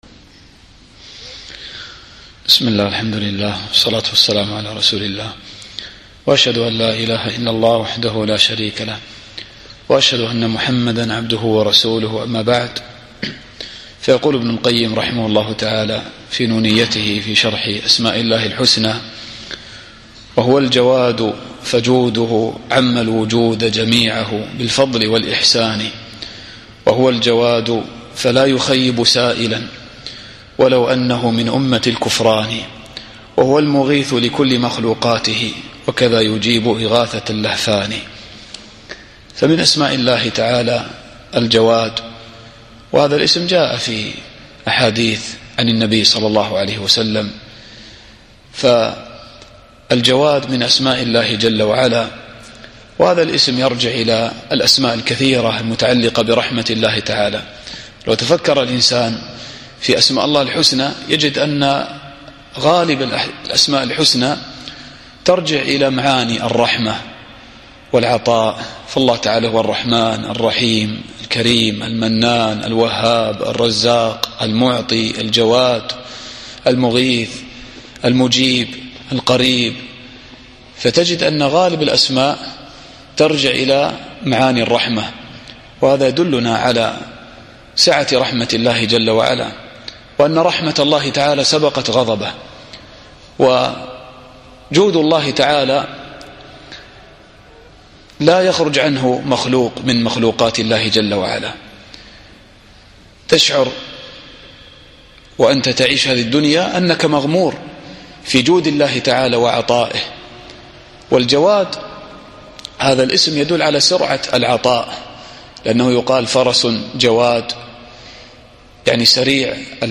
الدرس الثامن والعشرون